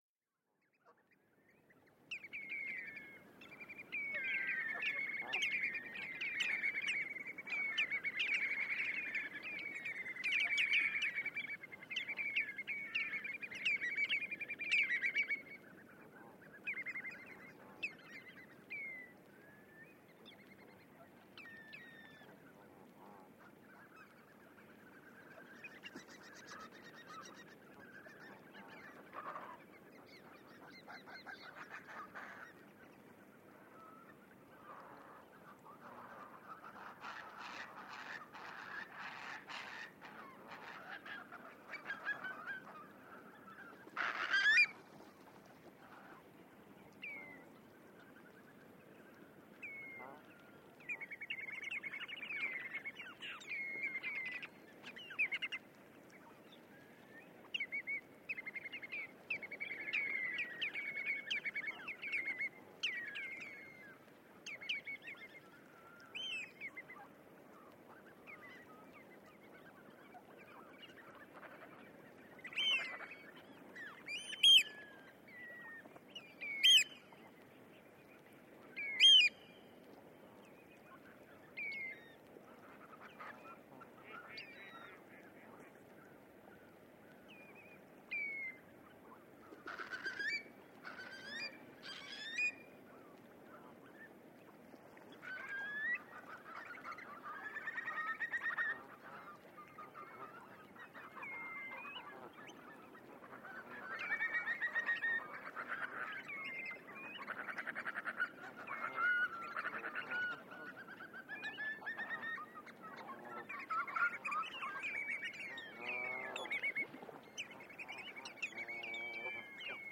Waders leaving roost at Cresswell
Waders - a mix of mainly redshank, curlew, oystercatcher - leaving a roost at dawn on a high tide at Cresswell Pond, Northumberland. MKH60s/SD702.